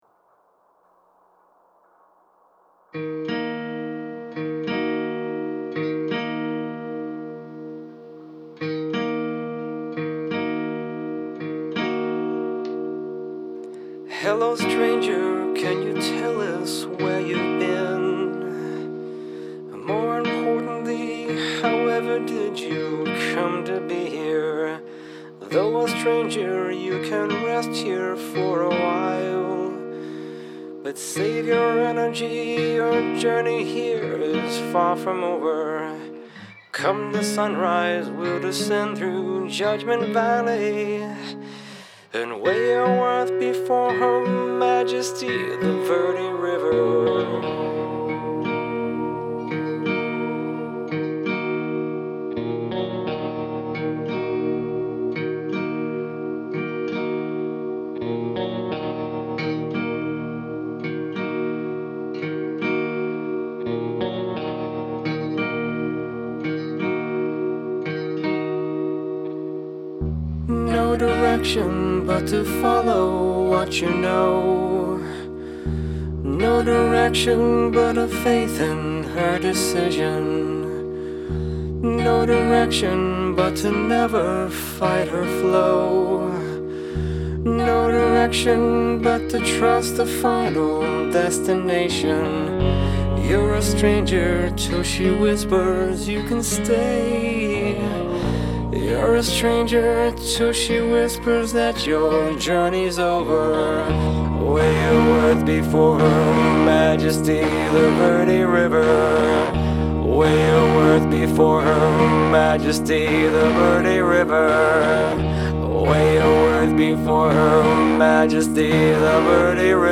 recorded in one of his wine cellars with his friends